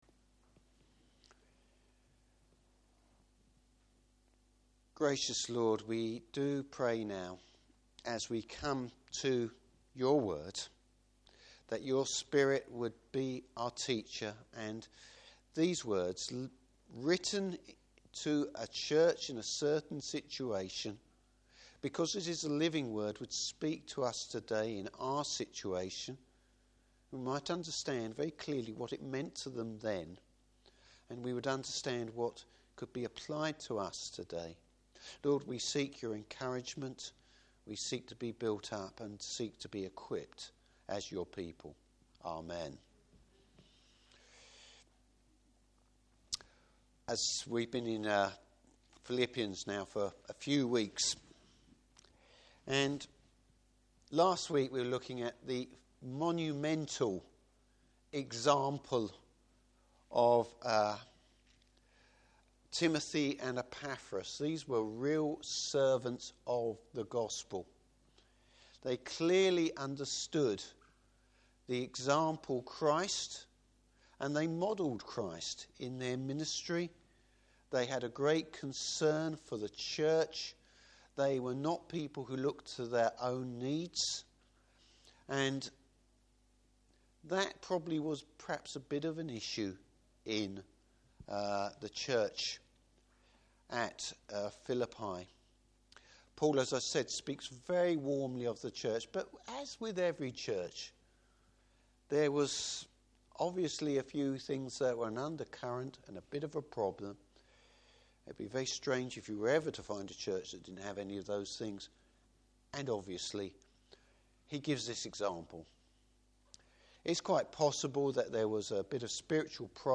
Service Type: Evening Service Paul places God’s grace above his former religious works!